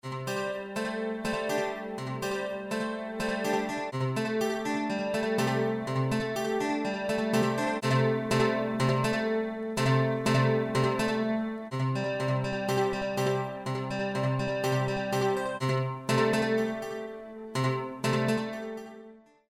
123bpm Chorus Guitar C Major.zip
10 Royalty Free Chorus Guitar Loops in C Major.
123-bpm-Chorus-Guitar.mp3